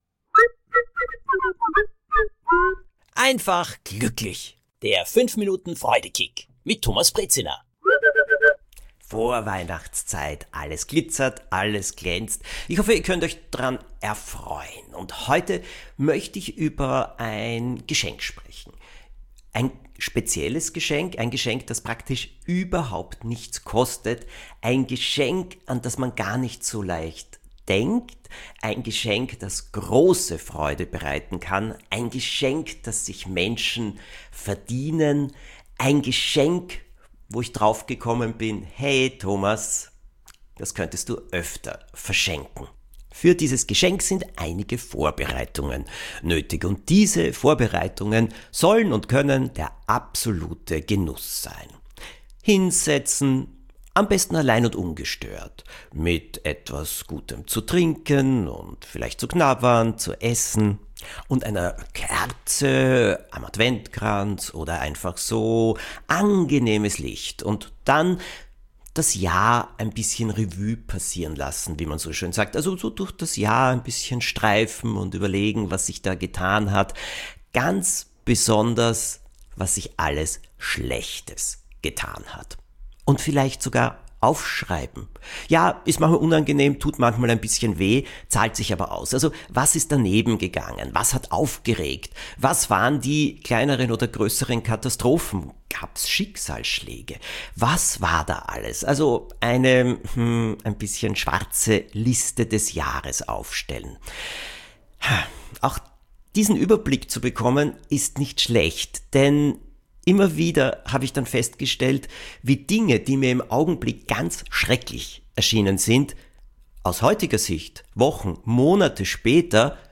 von und mit Thomas Brezina